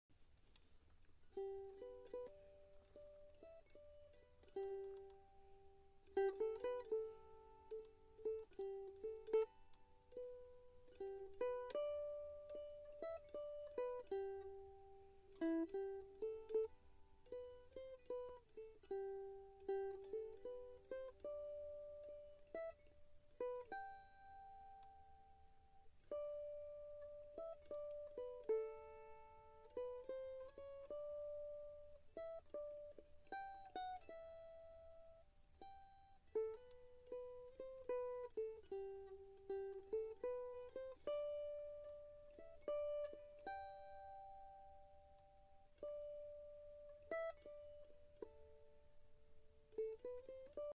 Daily Devotional
Instrumental Mandolin